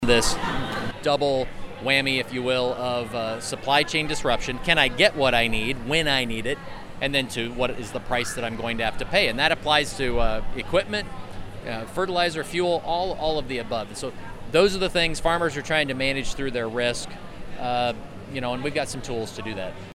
Naig spoke with Radio Iowa at the Iowa State Fair.